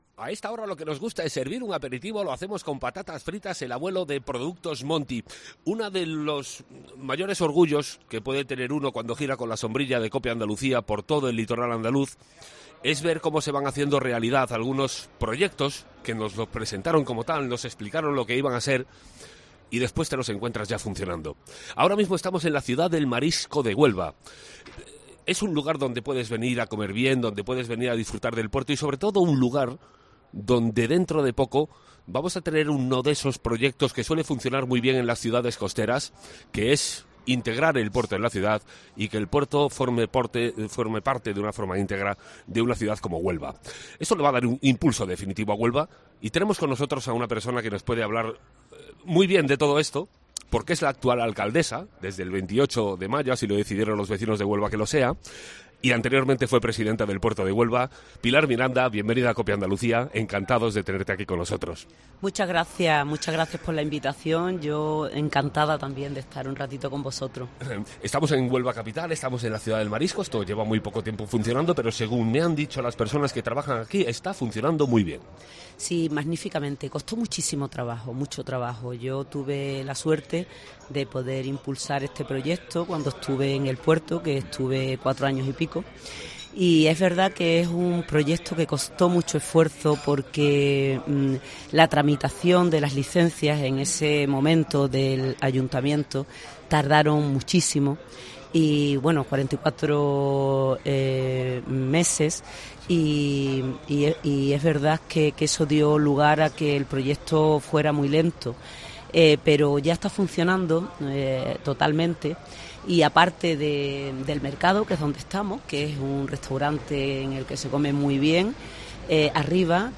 Recibimos a la alcaldesa de Huelva, Pilar Miranda, en la recién inaugurada Ciudad del Marisco.
Nuestra sombrilla se ha instalado hoy en la Playa de las Marismas del Odiel.